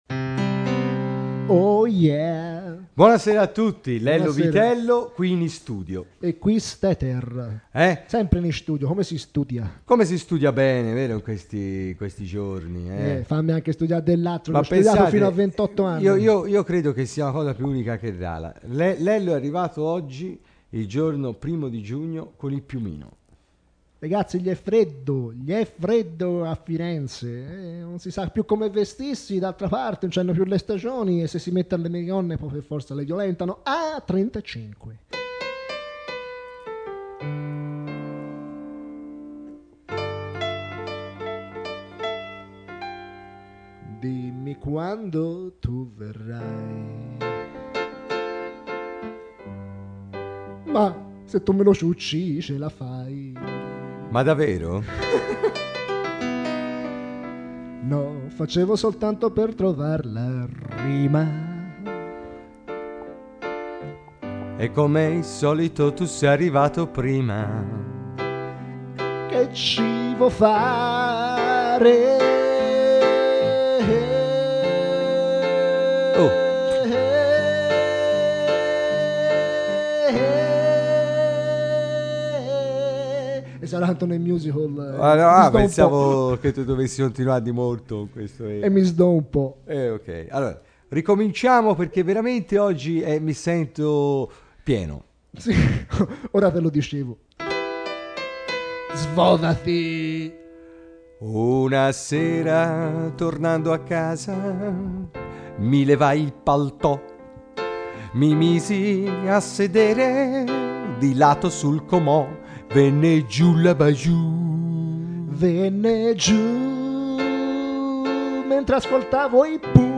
canzoni nate sul momento e dialoghi surreali